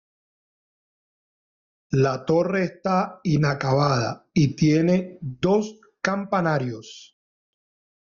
i‧na‧ca‧ba‧da
/inakaˈbada/